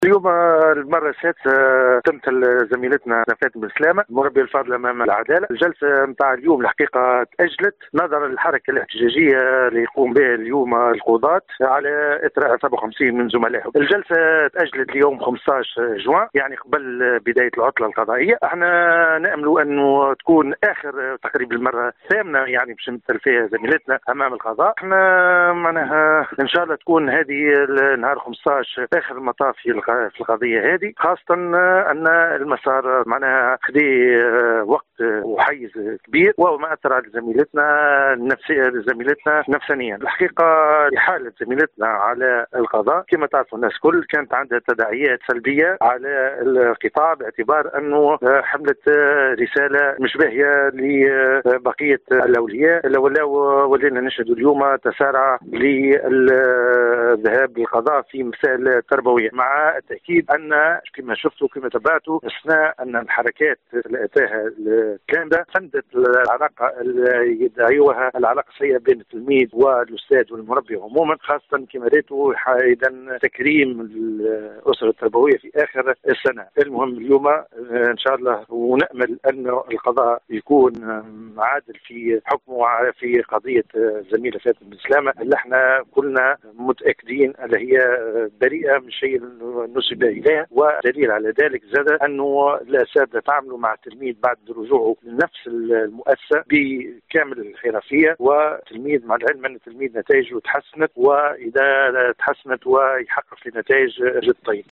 في تصريح ل “ام اف ام”